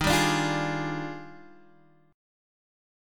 D# Major 11th
D#M11 chord {x 6 5 7 6 4} chord